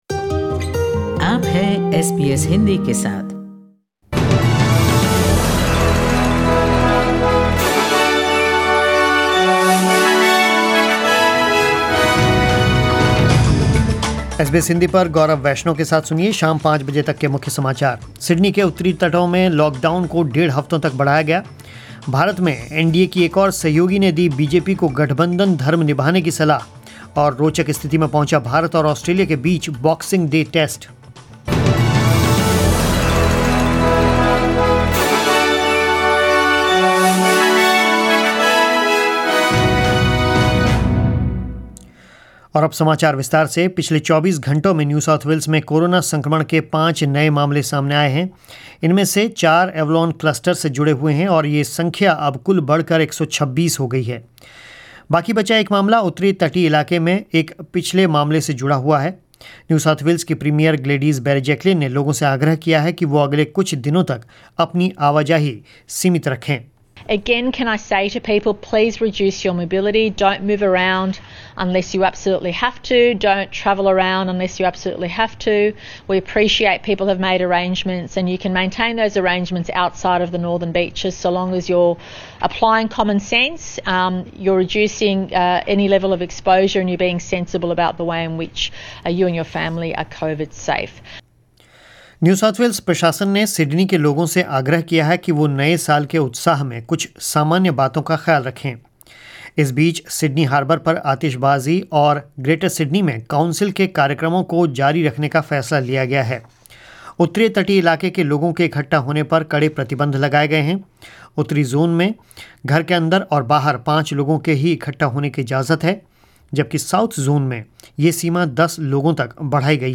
News in Hindi 28 December 2020 ** Janata Dal United leader KC Tyagi on Sunday said the exit of six JDU MLAs in Arunachal Pradesh and their joining the BJP is not a good sign for alliance politics. // **** A lockdown in Sydney's northern beaches extended for at least another week and a half.